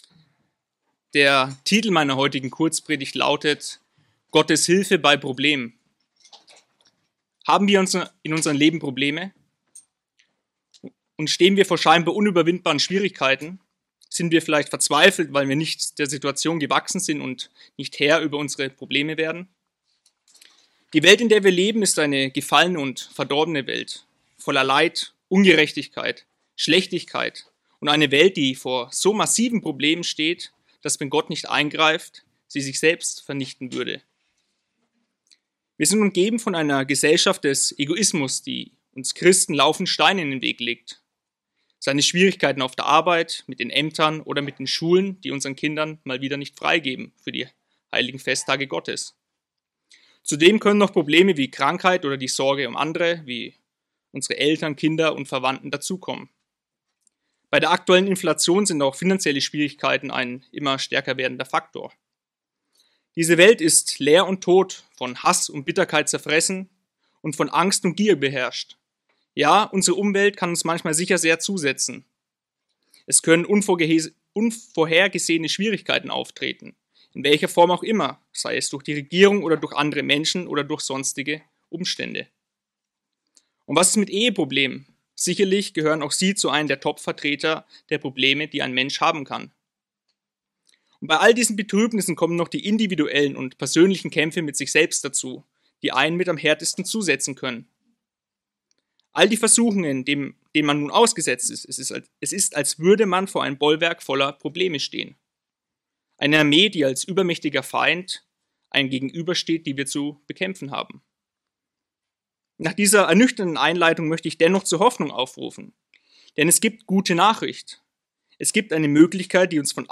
Diese Kurzpredigt soll uns aufzeigen, an wen wir uns bei Problemen wenden müssen, und wie wir dies in richtiger Weise tun.